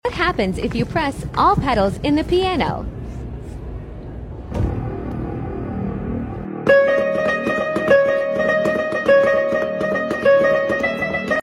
When Piano Has Only 2 Sound Effects Free Download